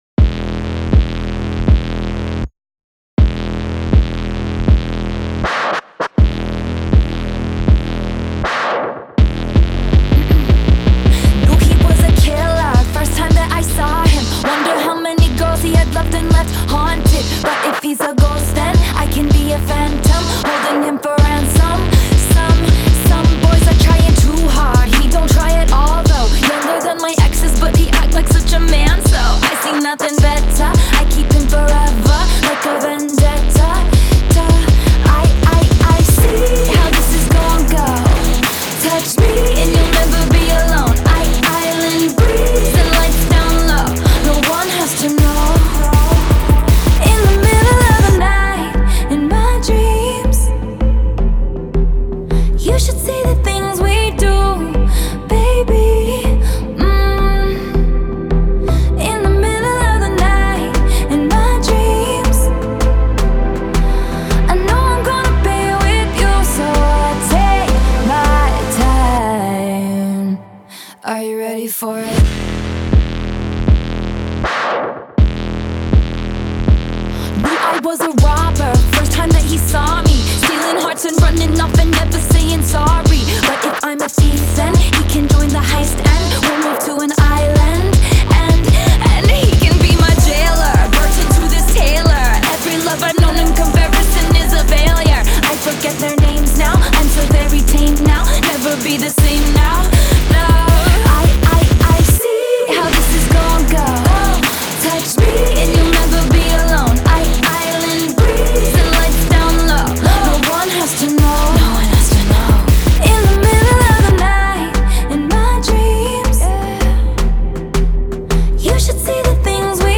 ژانر: پاپ / راک